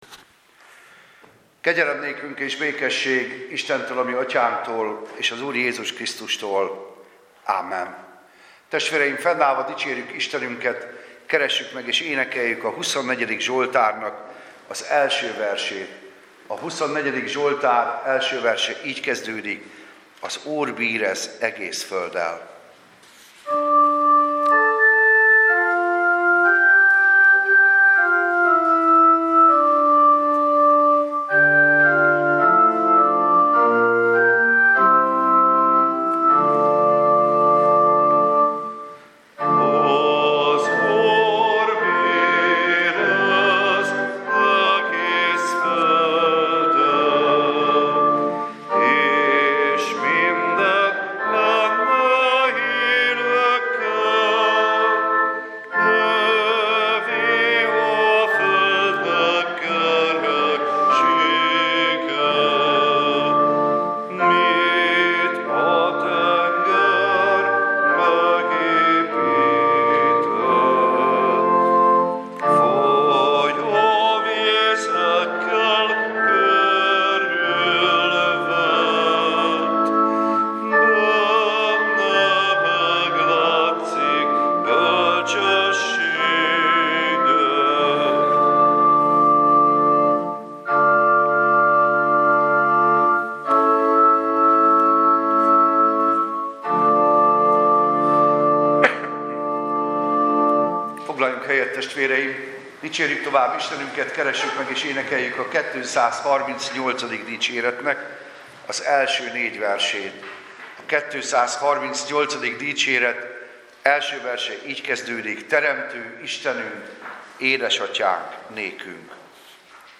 Service Type: Igehirdetés